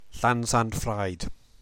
To hear how to pronounce Llansantffraed, press play: